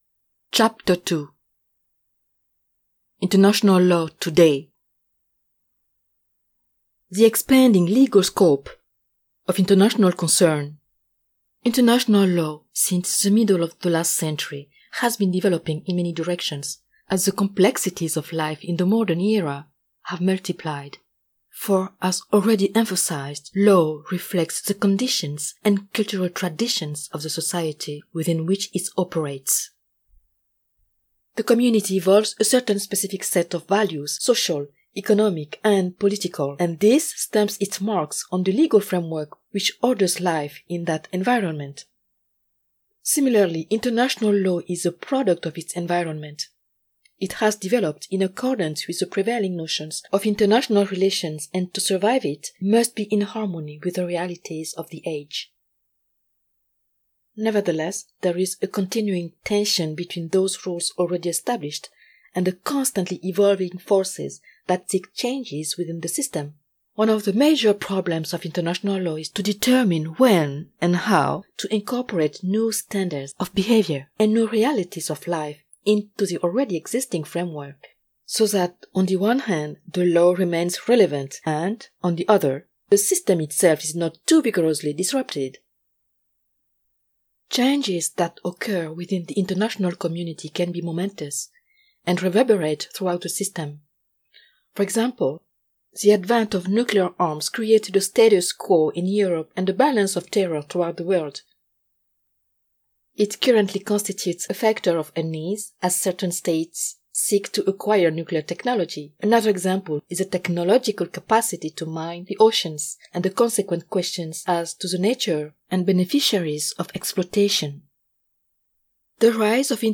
Audio Book Samples
Authoritative, Warm
Audiobook_International-Law_Chapter-2.mp3